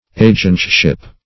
agentship - definition of agentship - synonyms, pronunciation, spelling from Free Dictionary
\a"gent*ship\ ([=a]"jent*sh[i^]p)